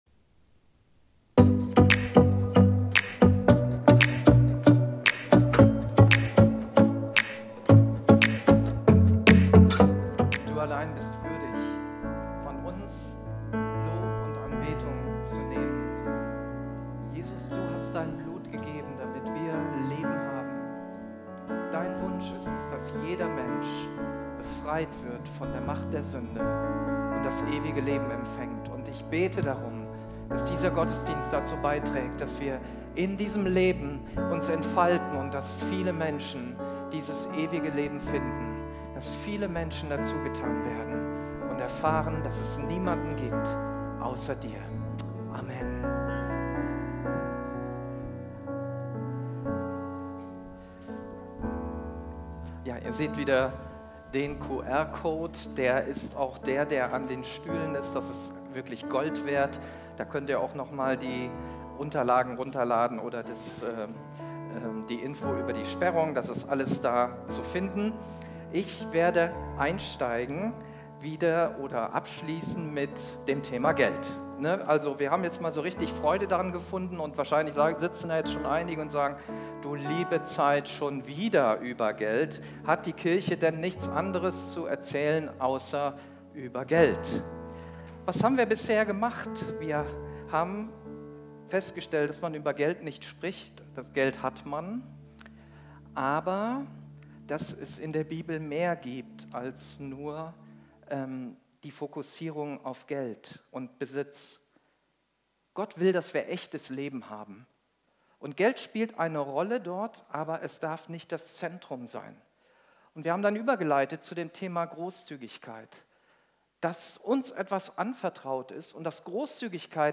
Video und MP3 Predigten